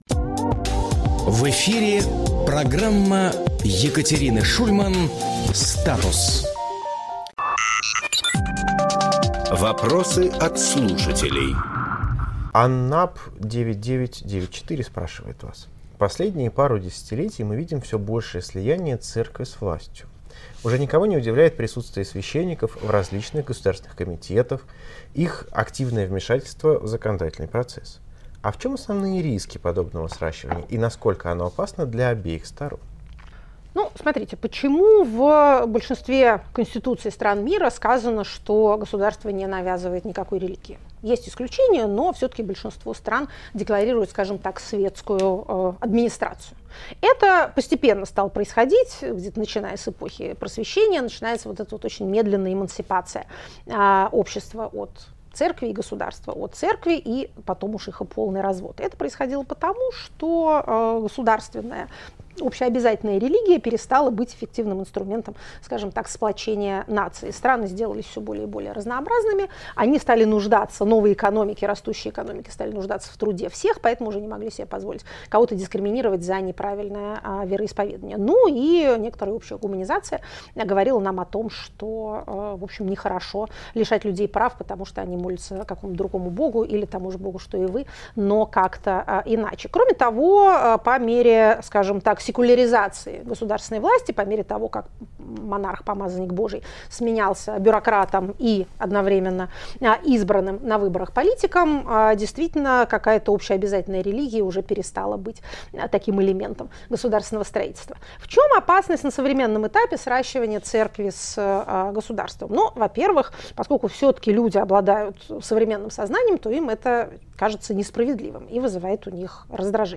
Екатерина Шульманполитолог
Фрагмент эфира от 07.01